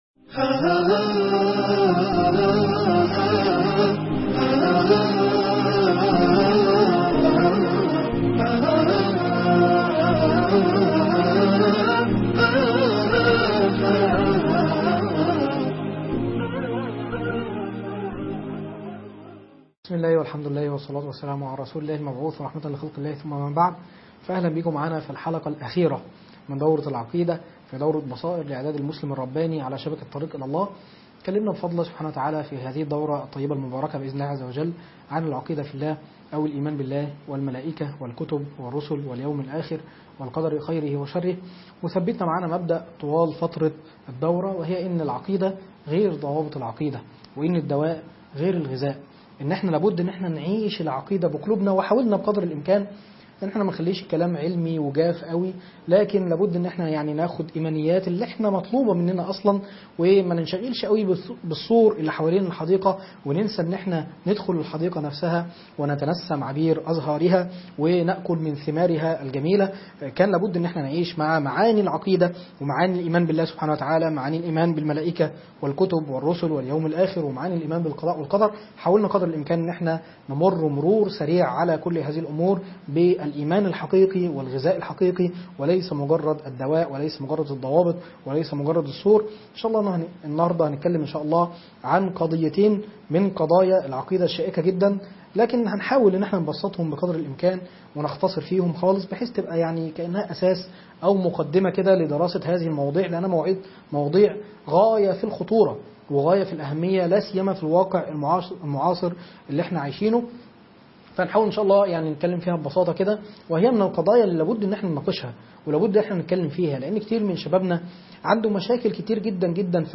الولاء والبراء والايمان والكفر ( محاضرة 6